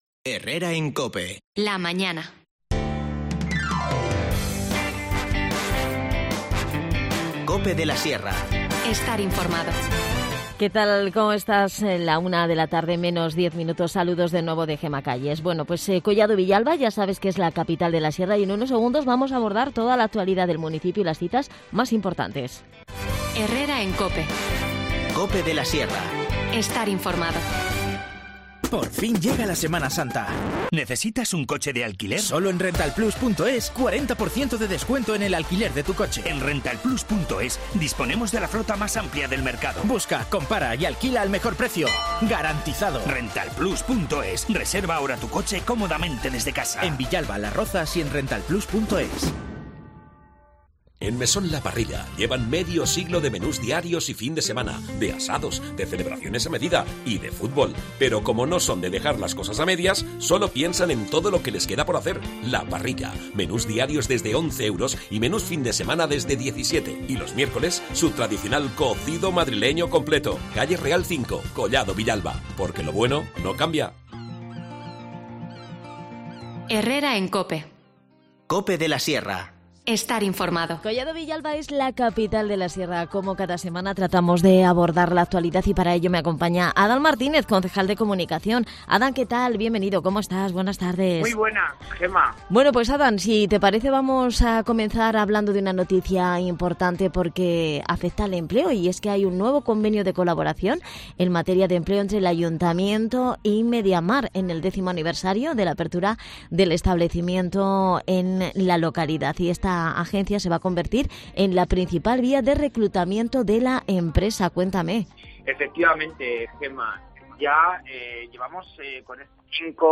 De estas y otras cuestiones hemos hablado con Adan Martínez, concejal de Comunicación.